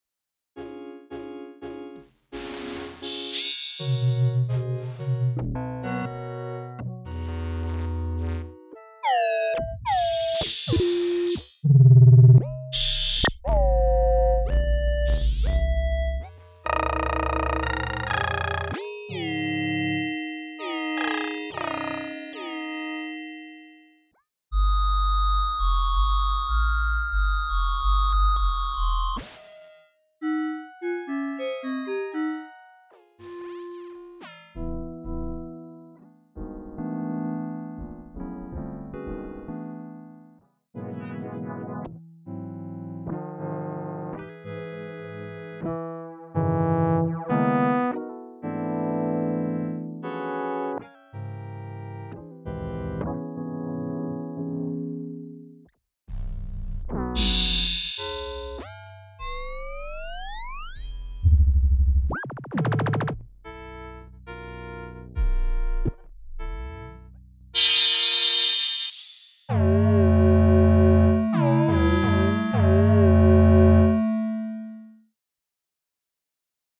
Short example of how fast those sounds can be generated, once you find something useful you can fine tune it.